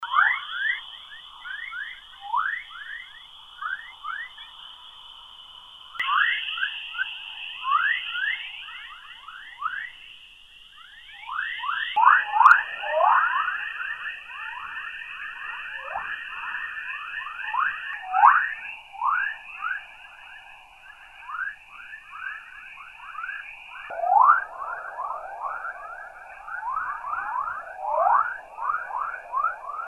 Dans le vide sidéral, on peut aussi capter le bruit de notre planète. On appelle cela le Chorus.
Ce sont des ondes électromagnétiques qui sont émises par le champ magnétique terrestre.
À l’aide de satellites, ces ondes radios très faibles ont été captées puis transmises à la NASA qui les a ensuite converties en ondes sonores.
Cette onde électromagnétique se situe entre 300 et 1000 Hz, ce qui la fait entrer dans la catégorie des ondes radio Ultra Basse Fréquence (UBF).
Un chant d’oiseaux ? Un chant d’animaux de la mer ?
Chorus.mp3